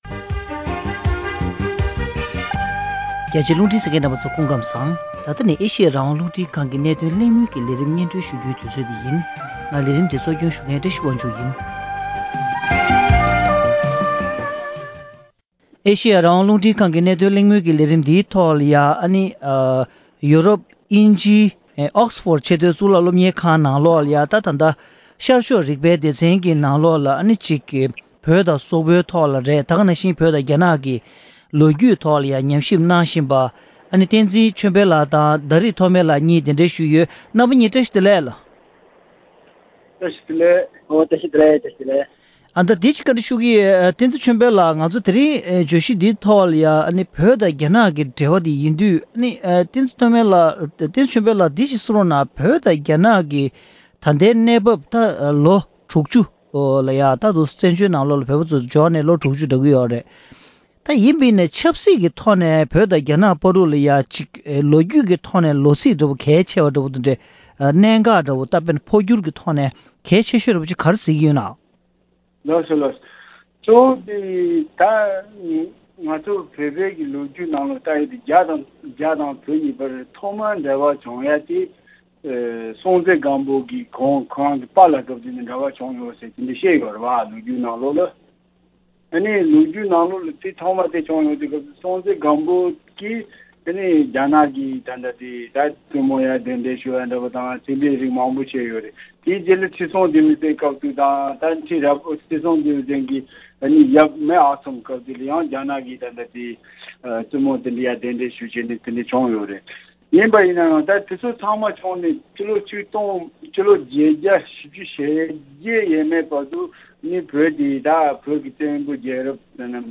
༄༅། །ཐེངས་འདིའི་གནད་དོན་གླེང་མོལ་གྱི་ལས་རིམ་ནང་།